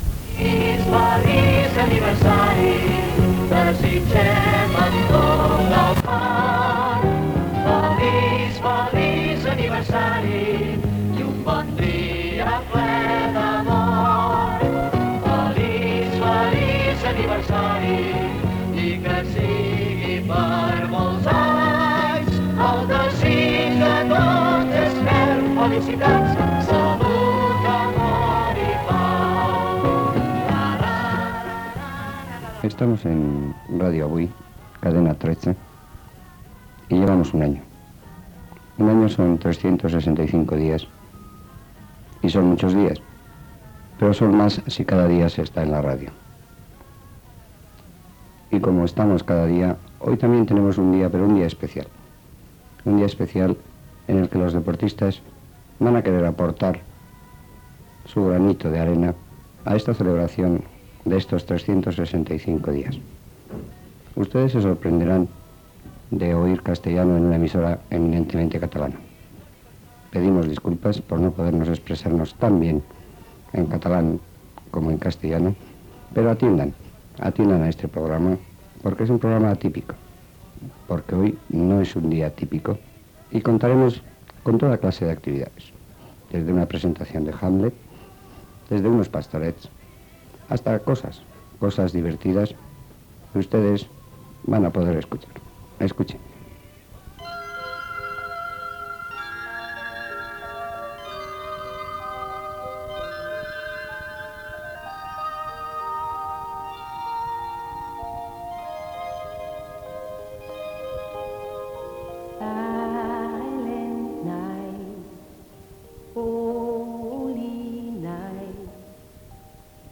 Espai humorístic en el primer aniversari de Ràdio Avui Cadena 13, amb un fragment dels pastorets i un fragment de Shakespeare
Entreteniment
FM